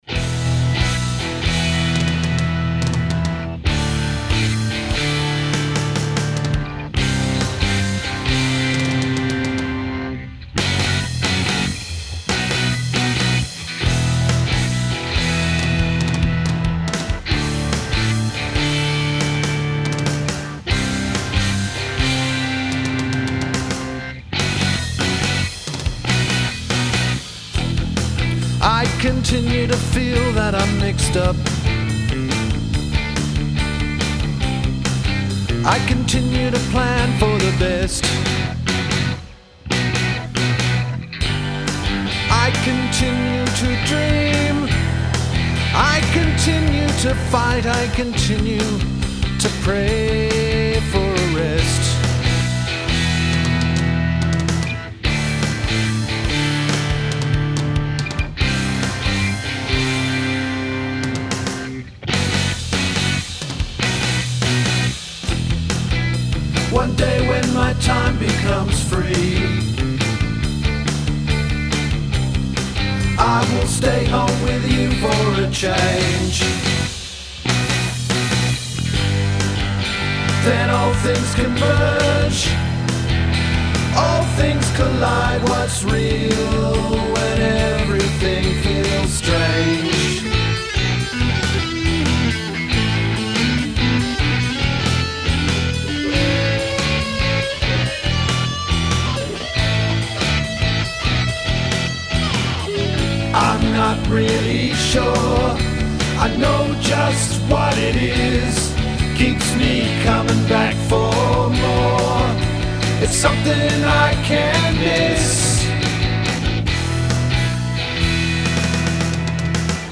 Vocals, Rhythm Guitar, Keyboards & Drums.
Vocals & Bass.
Lead guitar.